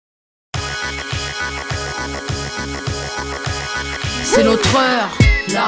Pop
исполняет страстно, с приятной хрипотцой